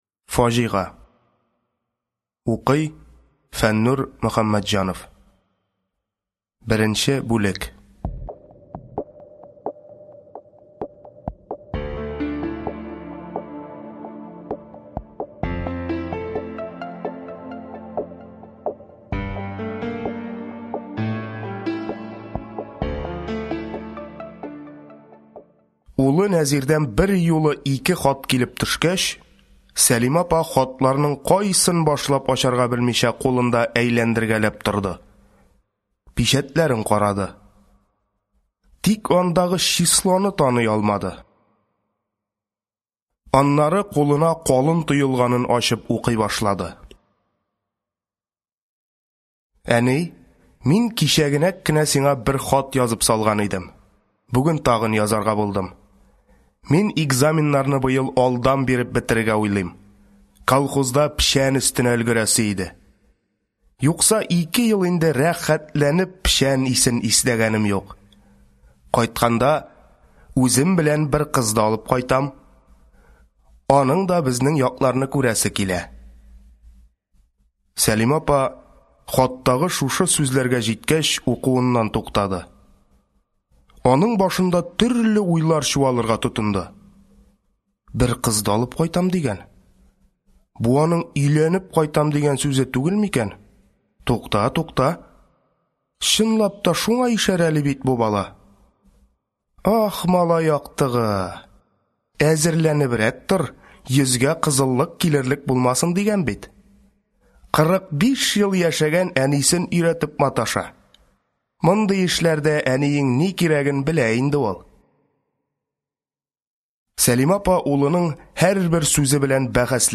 Аудиокнига Фаҗига | Библиотека аудиокниг